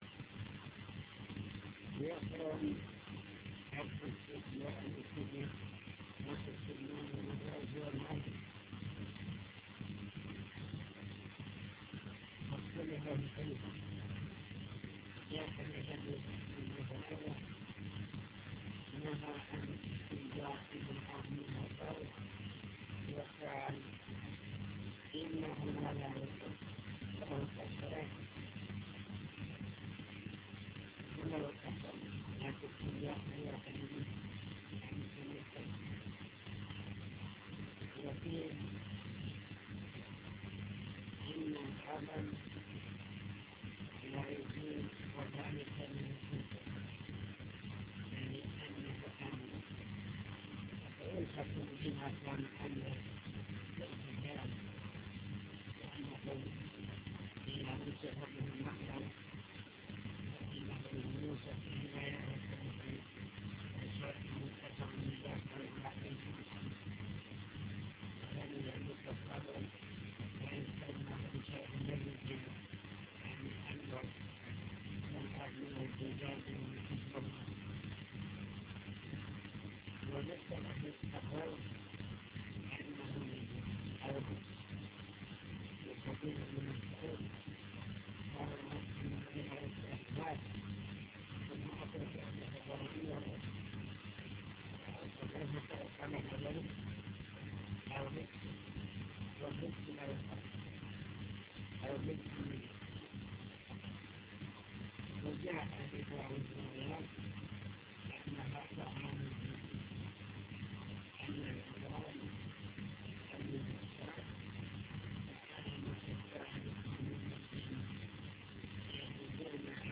المكتبة الصوتية  تسجيلات - كتب  شرح كتاب دليل الطالب لنيل المطالب كتاب الطهارة باب الاستنجاء